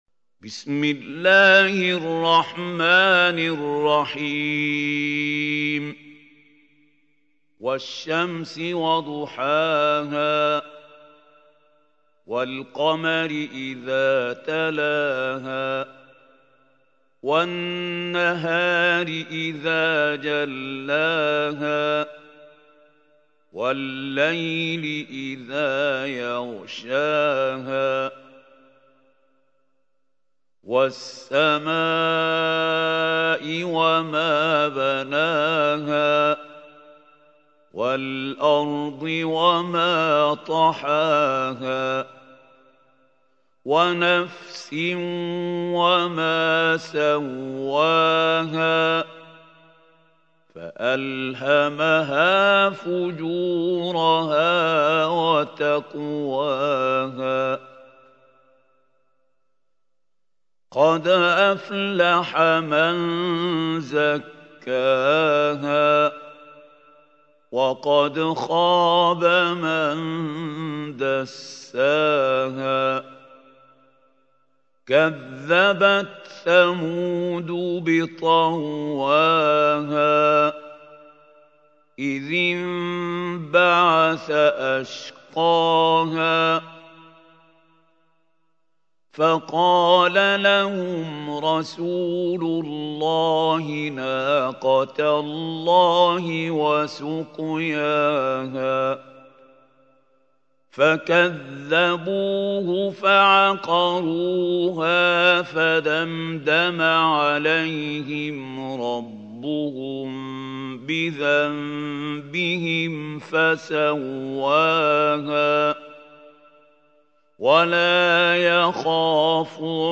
سورة الشمس | القارئ محمود خليل الحصري